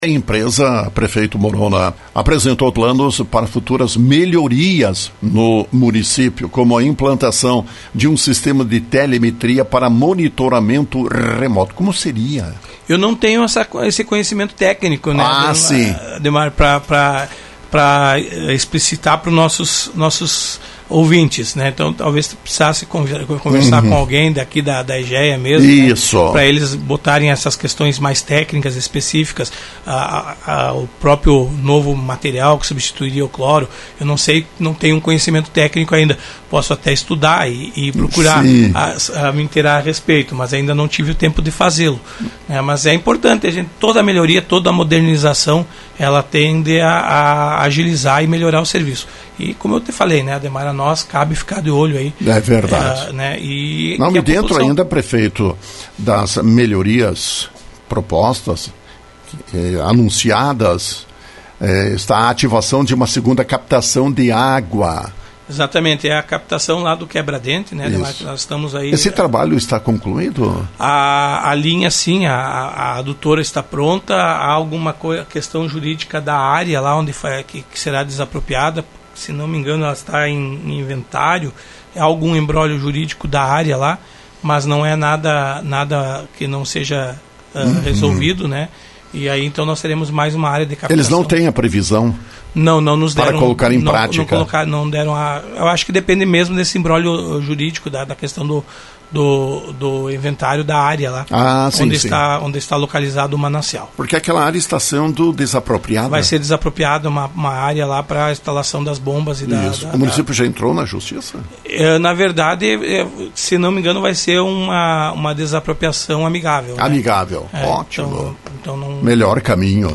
Conteúdo Jornalismo Rádio Lagoa FM
Da mesma forma antecipou futuras melhorias que serão implementadas no fornecimento de água em Lagoa Vermelha. Prefeito Morona comentou.